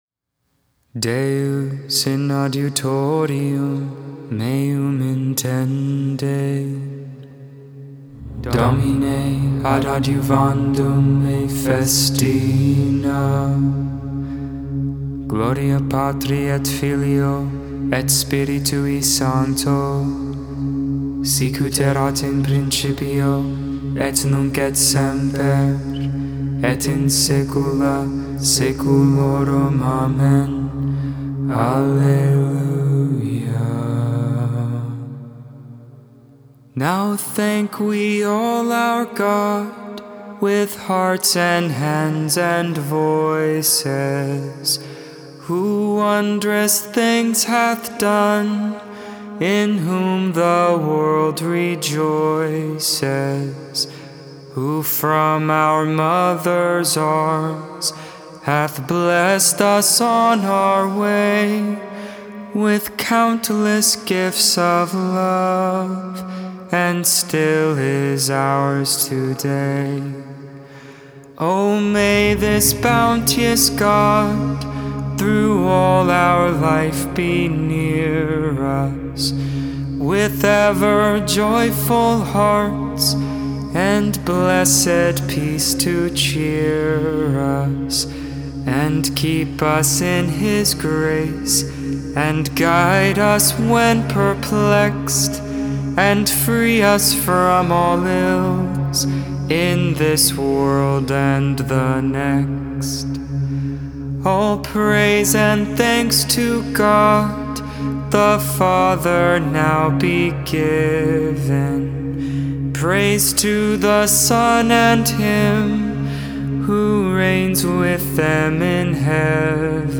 2.5.22 Vespers, Saturday Evening Prayer
Hymn: Now thank we all our God